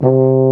BRS BARI C2.wav